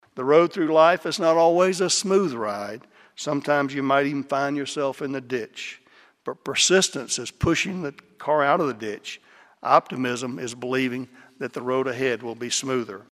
Kansas State welcomed U.S. Agriculture Secretary Sonny Perdue to campus Thursday as the 179th speaker for the Landon Lecture series.